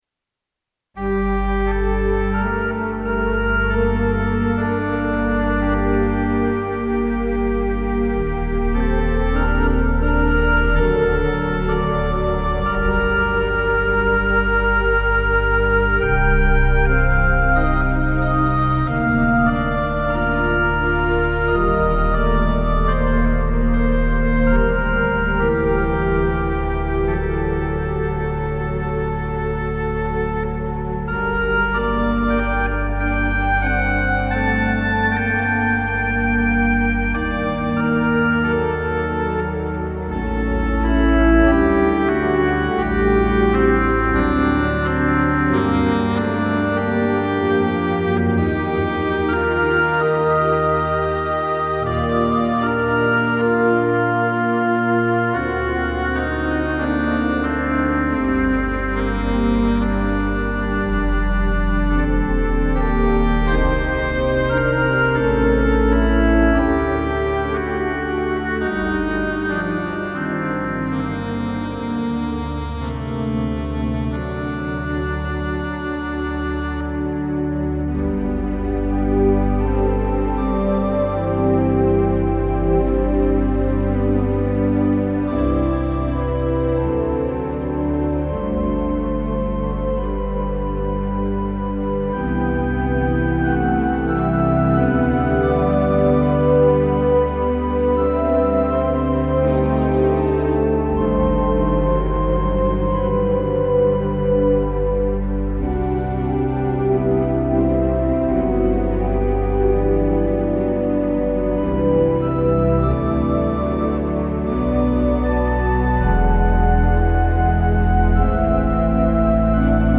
Kimball Organ • Basilica of St. Josaphat - Undercroft • Milwaukee, WI